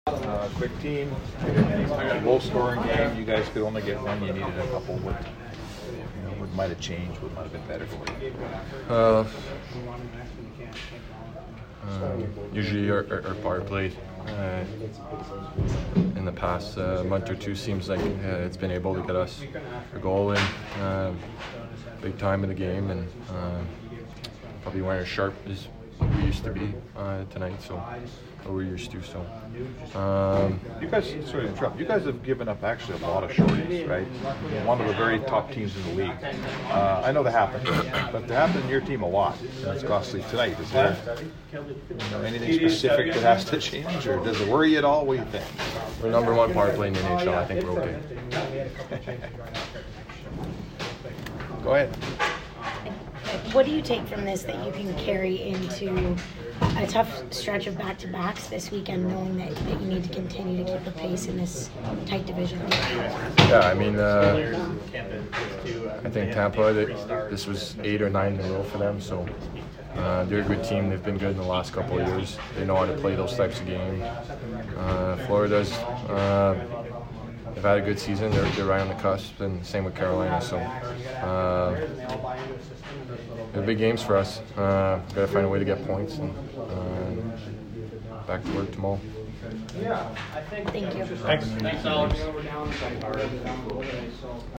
Alex Chiasson post-game 2/13